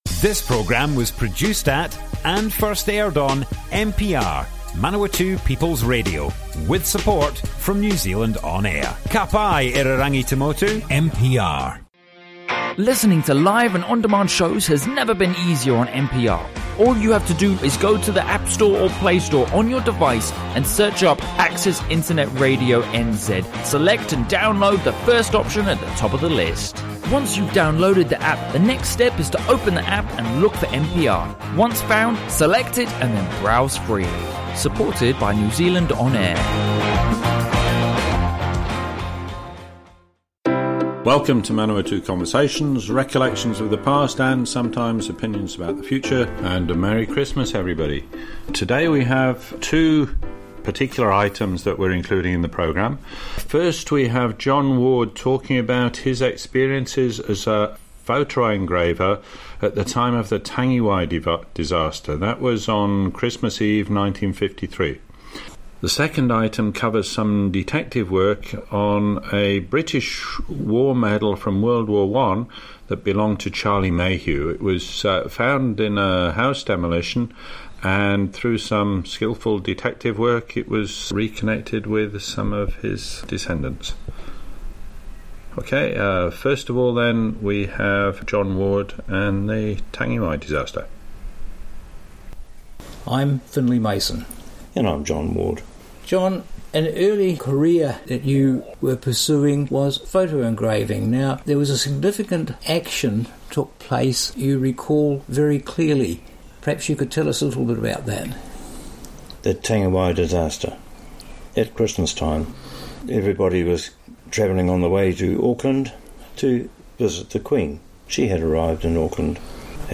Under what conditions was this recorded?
Broadcast on Manawatu Peoiple's Radio 25 December 2018.